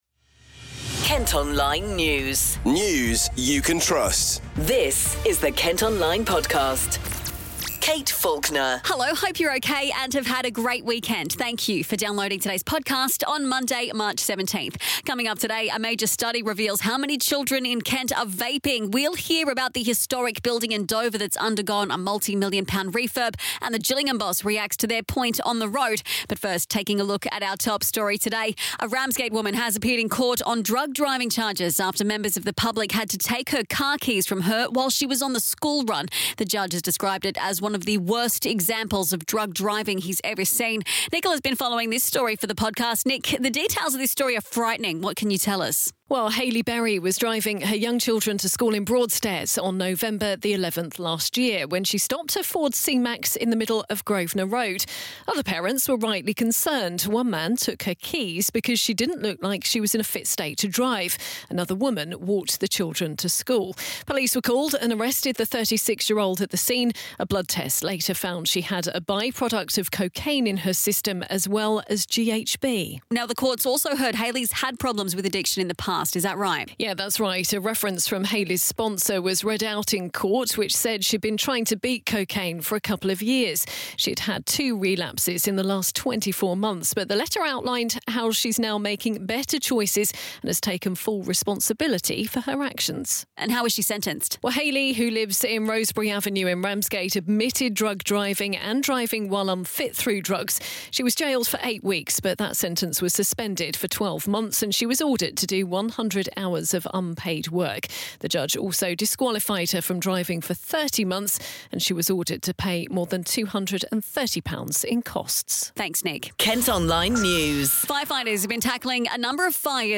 Also in today’s podcast, you can hear from a GP after a new study laid bare the extent to which Kent’s youngsters have become addicted to nicotine through vaping.